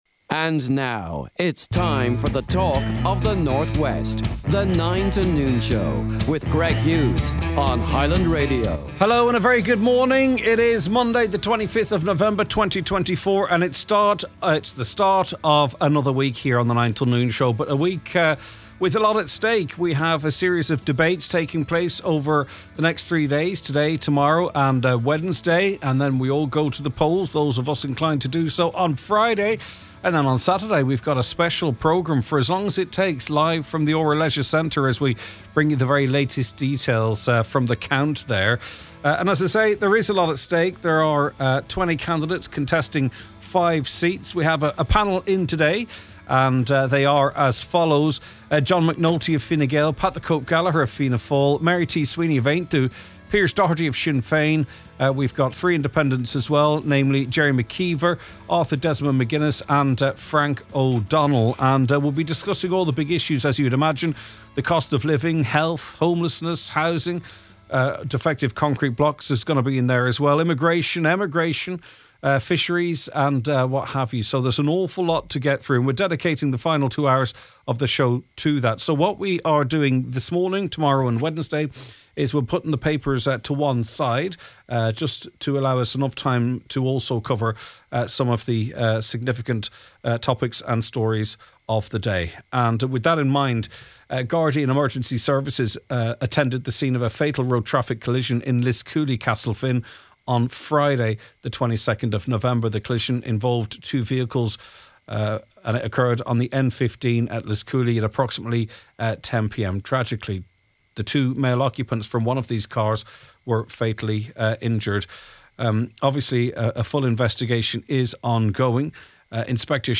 The Nine ’til Noon Show – Weekday’s 9am to 12noon Magazine type mid morning chat show which aims to inform and entertain listeners and to platform their views and issues :